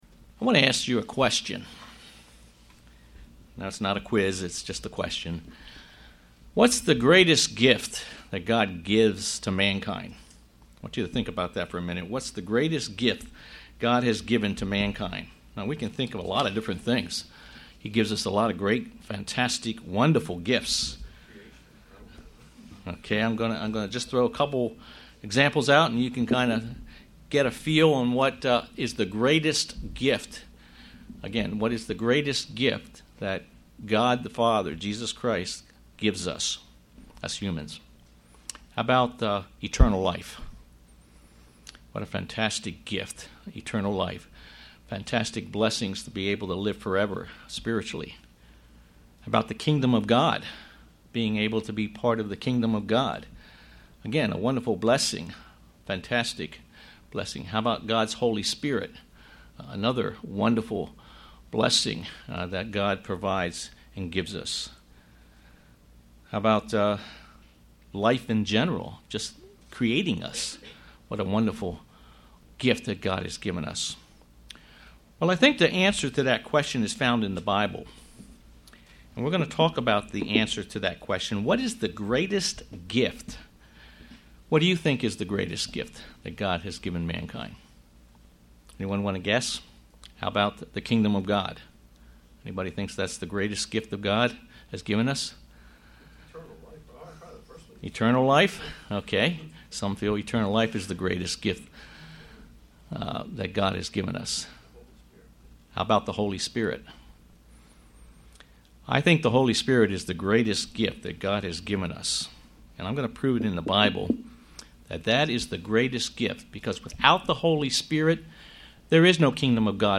Given in York, PA